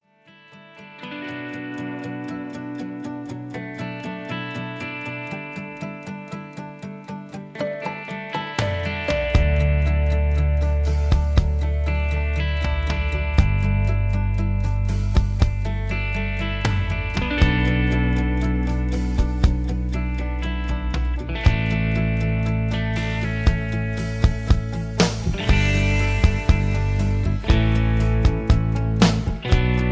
Key-D-E
mp3 backing tracks